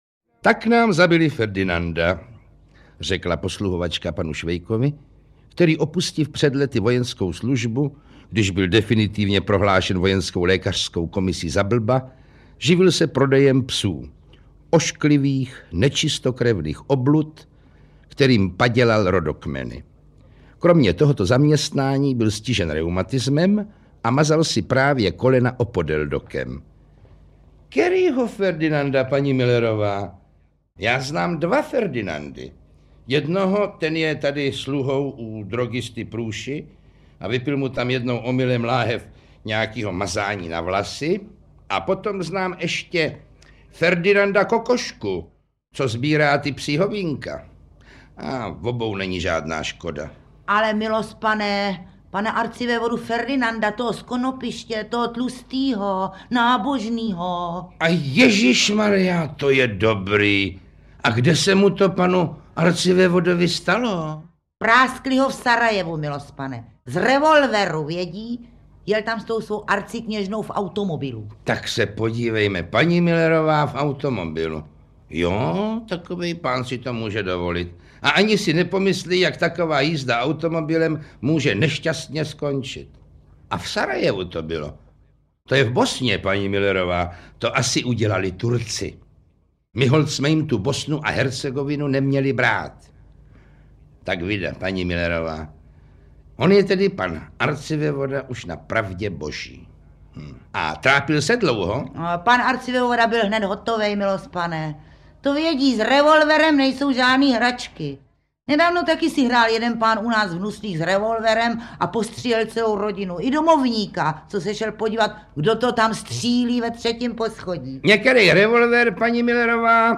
Ukázka z knihy
Kromě Jana Wericha četli některé postavy Vlasta Burian (polní kurát Katz), Jiřina Šejbalová (paní Müllerová) a František Filipovský (redaktor).